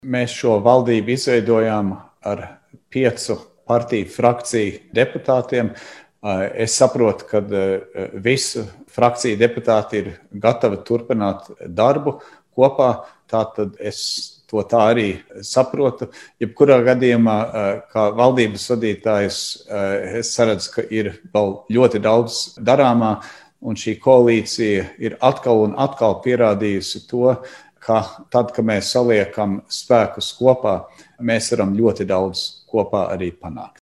Pēc koalīcijas sanāksmes tika paziņots, ka valdības koalīciju veidojošo visu piecu frakciju deputāti ir gatavi turpināt darbu kopā. Sīkāk Ministru prezidents Krišjānis Kariņš.